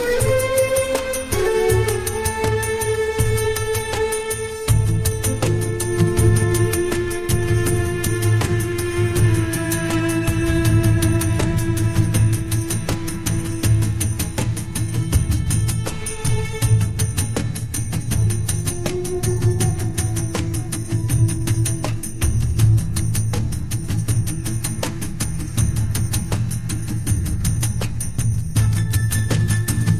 generate an ancient egyptian gypsy melody